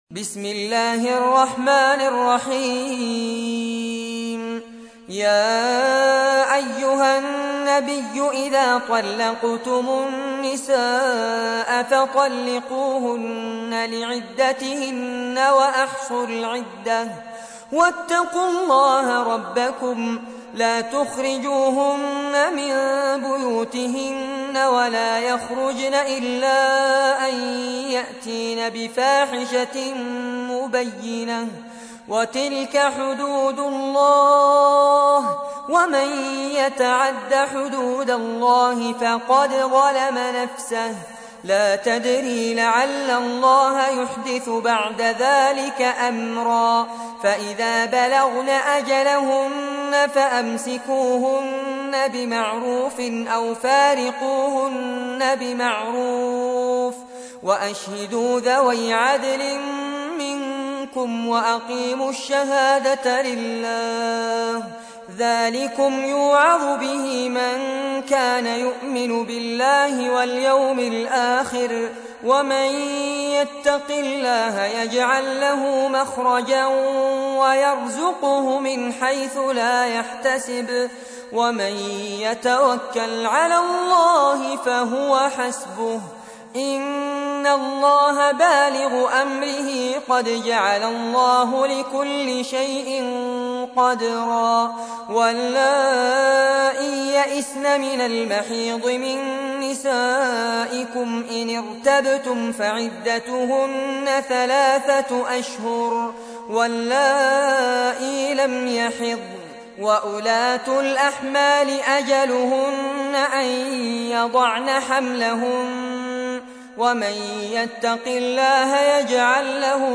تحميل : 65. سورة الطلاق / القارئ فارس عباد / القرآن الكريم / موقع يا حسين